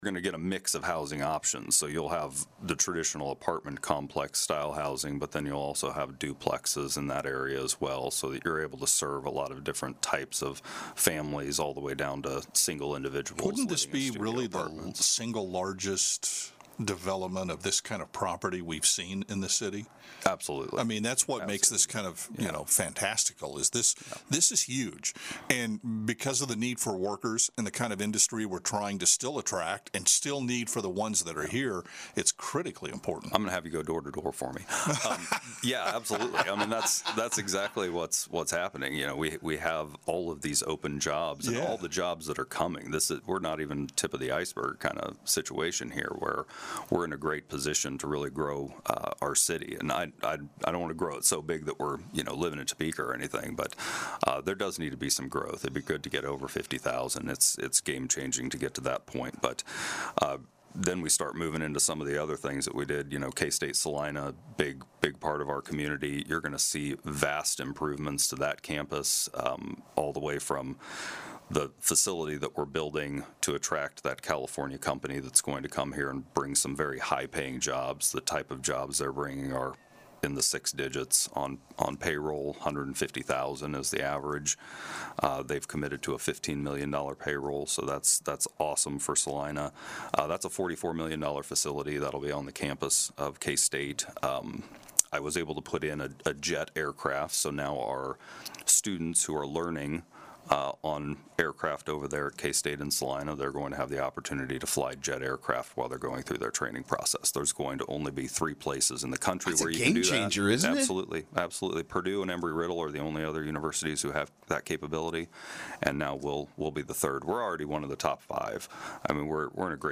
State Senator J.R. Claeys joined in on the KSAL Morning News Extra with a look at a host of topics including the backstory of funding for the project.